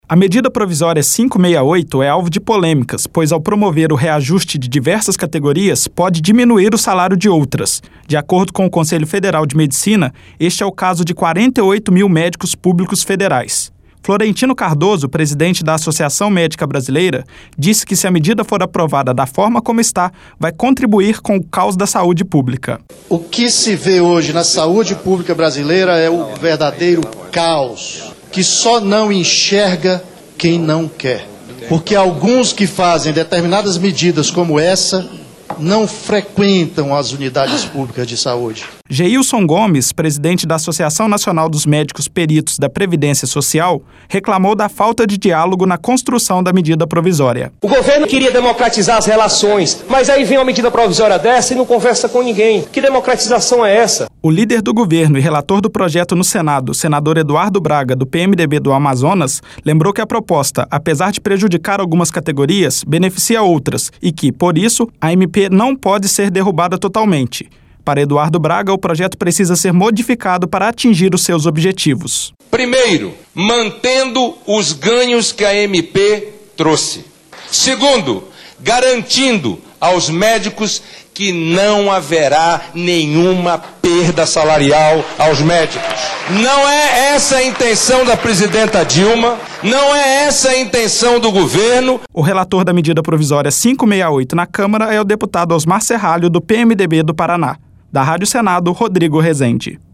LOC: REPRESENTANTES DE 11 ASSOCIAÇÕES PROFISSIONAIS ESTIVERAM NO CONGRESSO NACIONAL NESTA TERÇA-FEIRA PARA DEBATER A MEDIDA PROVISÓRIA 568. LOC: A PROPOSTA ALTERA O SALÁRIO DE 30 CATEGORIAS DO SERVIÇO PÚBLICO FEDERAL.